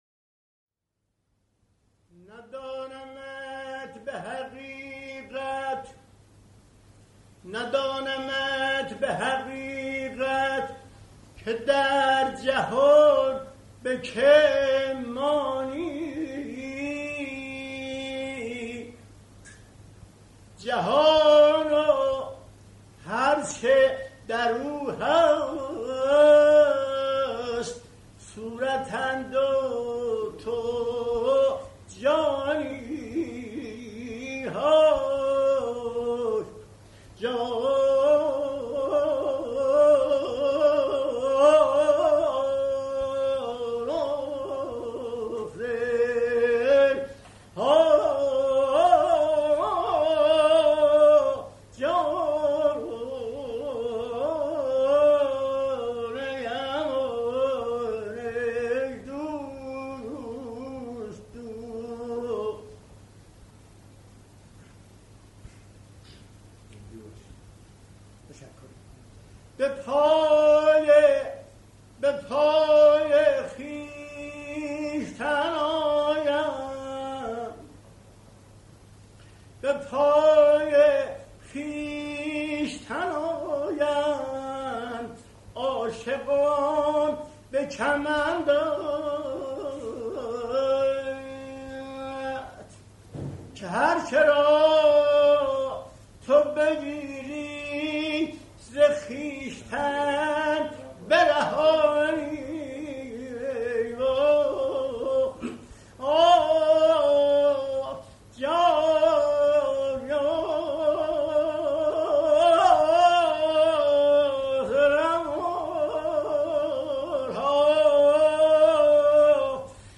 آواز تاج اصفهانی- در حضور امام موسی صدر - افشاری و عراق.mp3